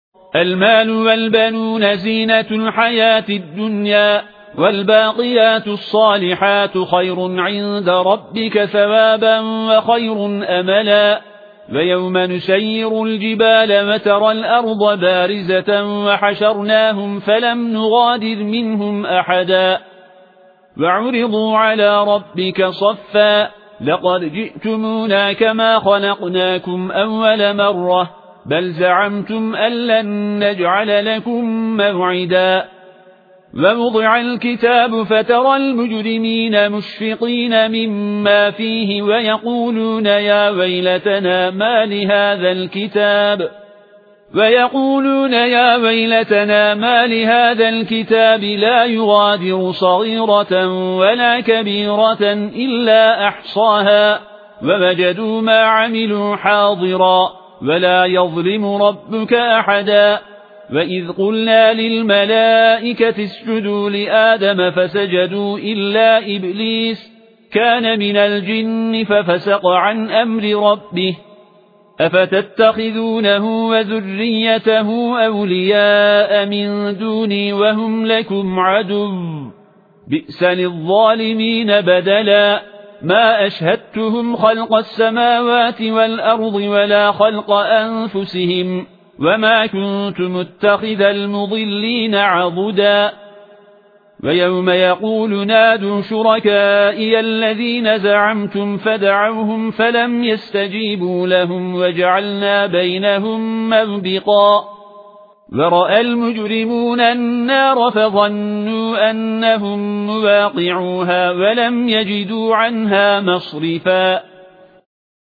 tilavetiyle sayfa sayfa olarak ayrılmış komple hatim seti.